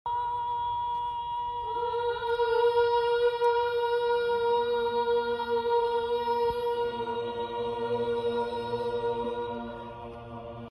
medieval chant